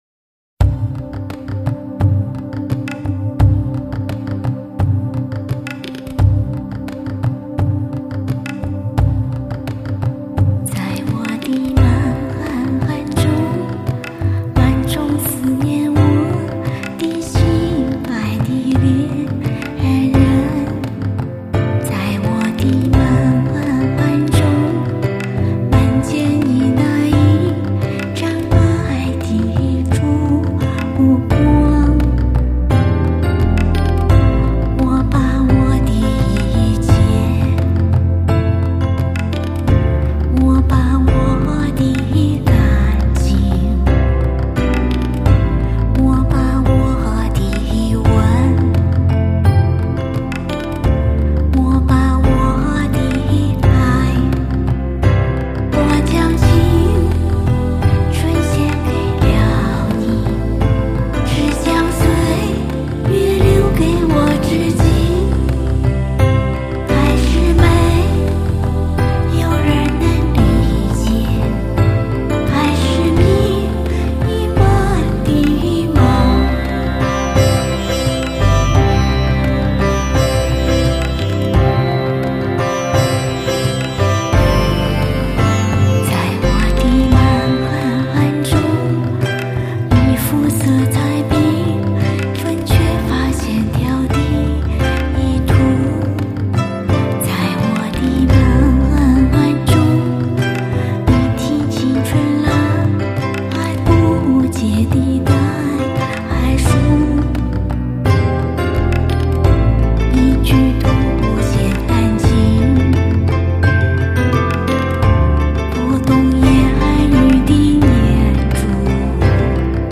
琵    琶
这首作品一开始让人听到的又是那种熟悉的和月标准节奏。
但是在制作上，加入了印度的西塔尔琴的勾勒，另外还有琵琶的渲染。